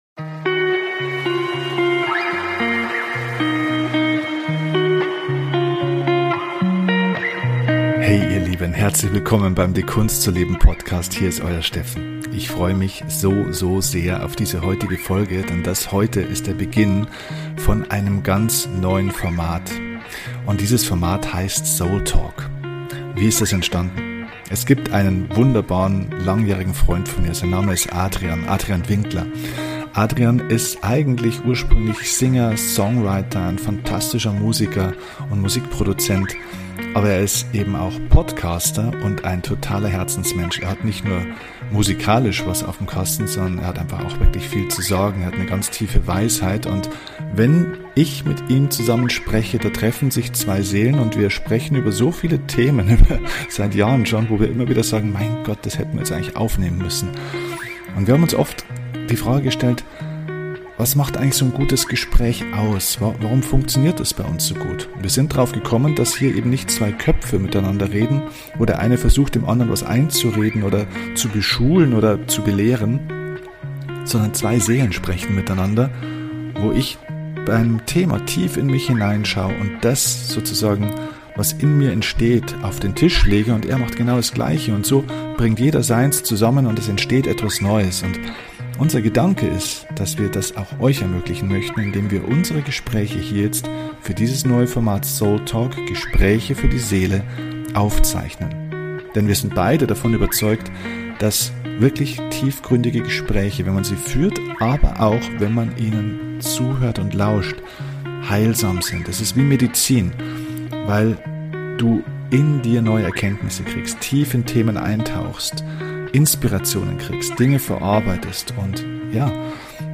Kein Skript, kein vorher ausgesuchtes Thema einfach treiben lassen.